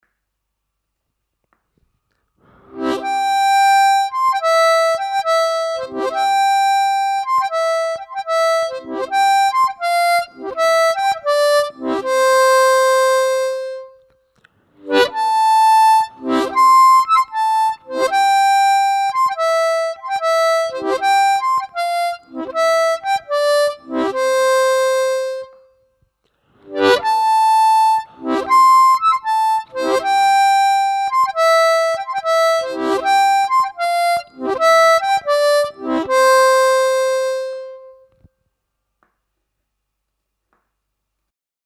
Слайд (глиссандо) и дроп-офф на губной гармошке
Сыграть мелодию «Маленькой ёлочке холодно зимой», в целях обучения используя технику глиссандо (слайд) перед каждой нотой.